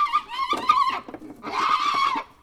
oz_negyhonaposgidaveszkialtasa00.02.wav